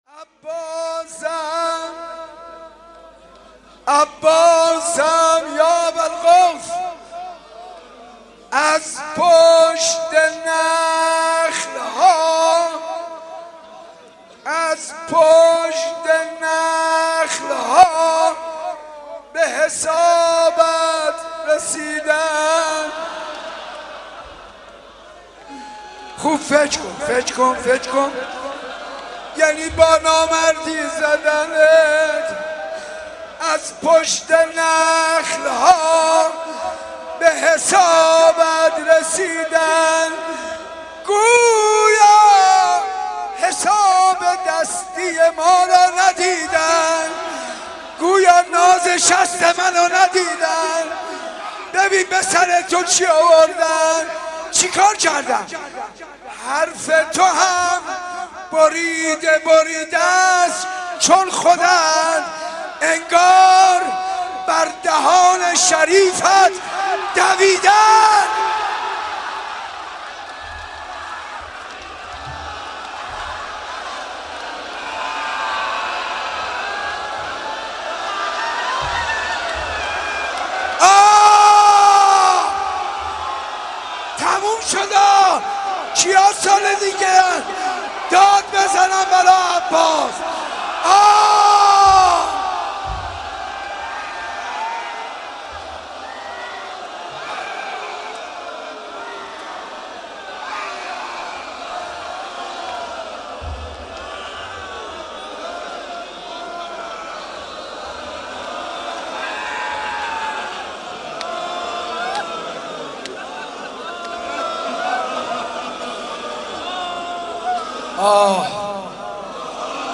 مناسبت : تاسوعای حسینی
مداح : حاج منصور ارضی قالب : روضه